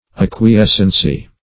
Search Result for " acquiescency" : The Collaborative International Dictionary of English v.0.48: Acquiescency \Ac`qui*es"cen*cy\, n. The quality of being acquiescent; acquiescence.